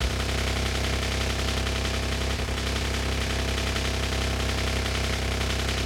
dieselOperate.ogg